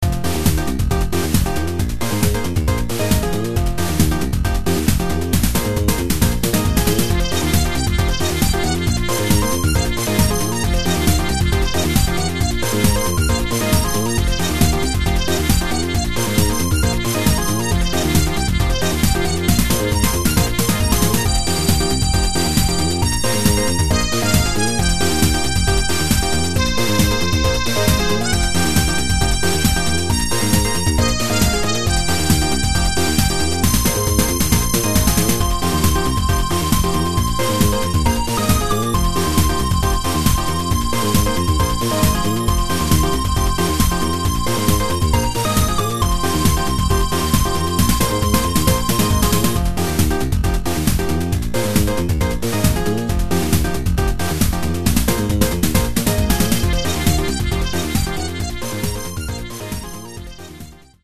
PSG
ハウス系のドラムをリズム音源で何とか鳴らそうとがんばっていたら